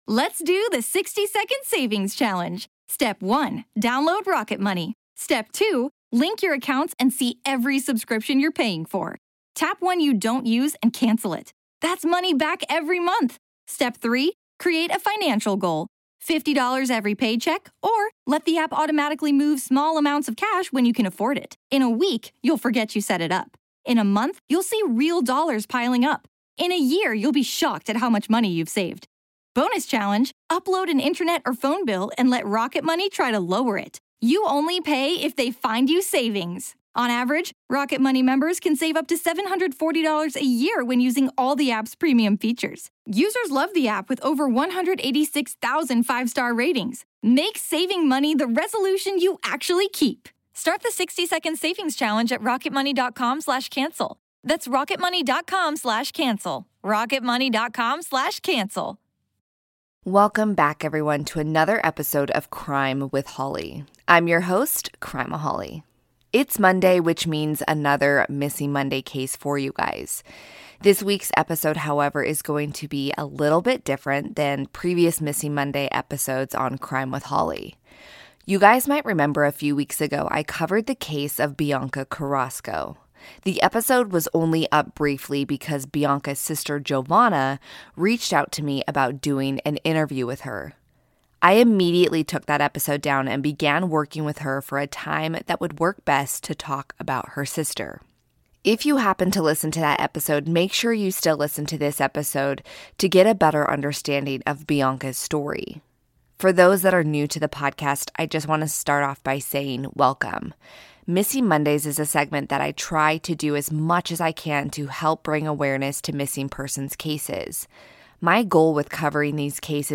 [Exclusive Interview]